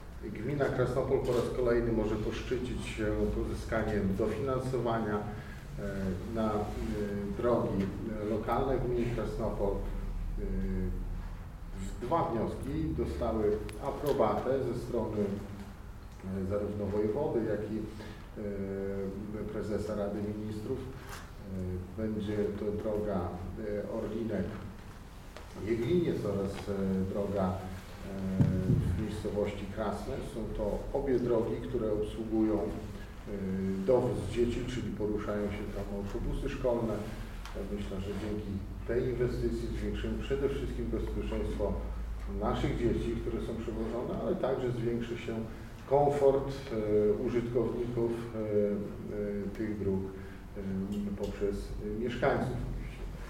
We wtorek, 22 lutego w Starostwie Powiatowym w Suwałkach odbyła się konferencja dotycząca projektów, które uzyskały dofinansowanie.
Wójt gminy Krasnopol, Karol Szrajbert o inwestycjach gminnych: